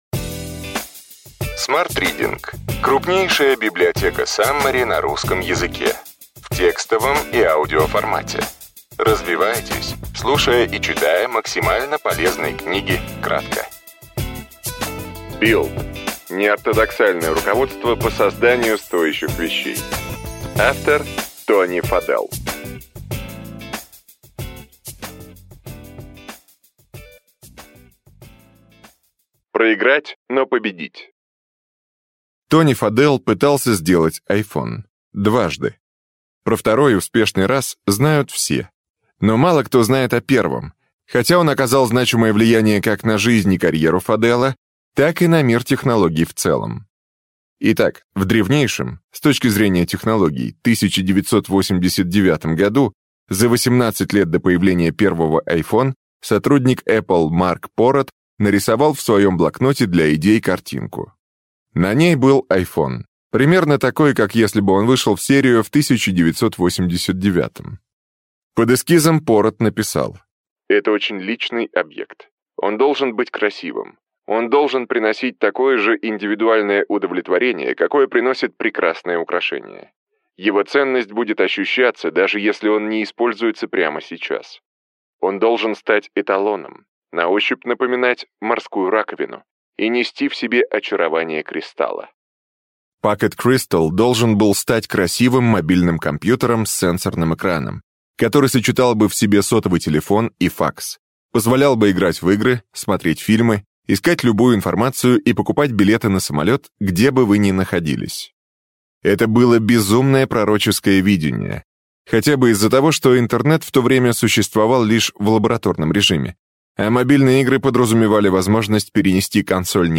Аудиокнига Build. Неортодоксальное руководство по созданию стоящих вещей. Тони Фаделл. Саммари | Библиотека аудиокниг